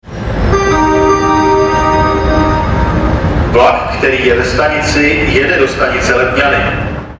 - Staniční hlášení o pobytu soupravy směr Letňany si